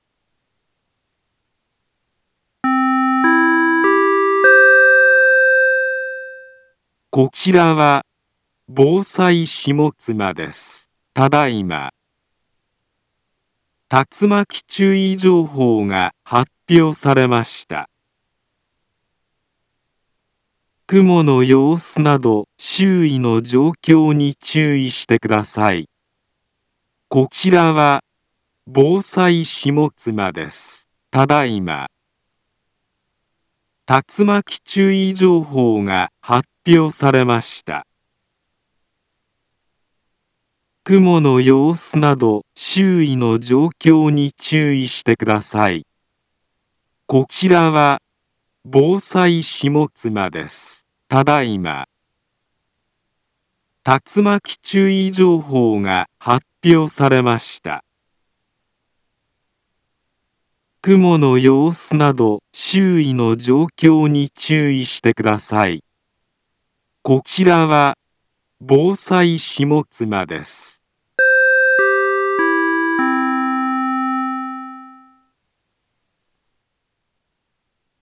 Back Home Ｊアラート情報 音声放送 再生 災害情報 カテゴリ：J-ALERT 登録日時：2023-11-17 13:15:18 インフォメーション：茨城県南部は、竜巻などの激しい突風が発生しやすい気象状況になっています。